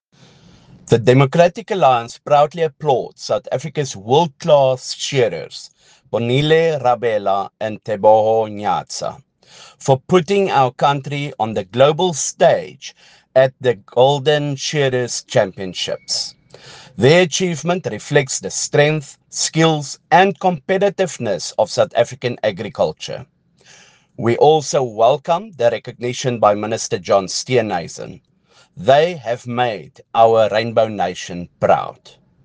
Afrikaans soundbite by Beyers Smit MP